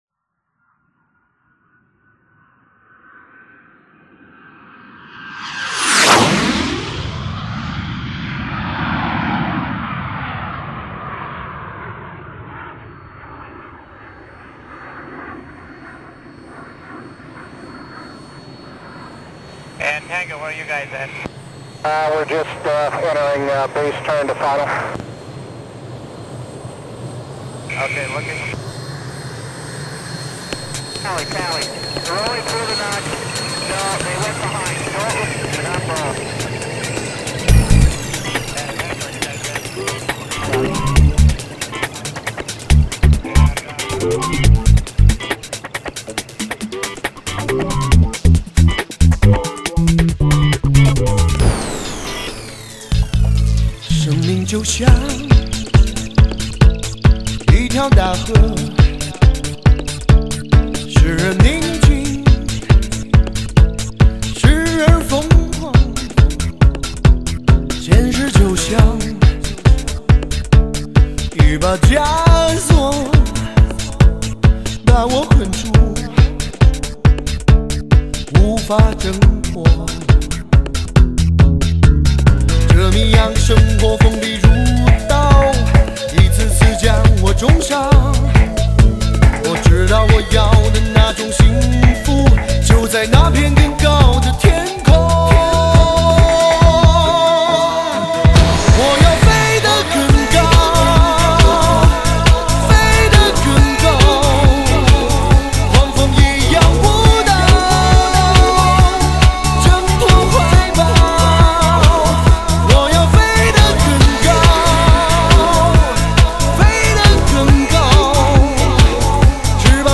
至HI舞曲 HIFI录制 最IN潮流
创新重装最受欢迎全嗨的士高发烧汽车音乐!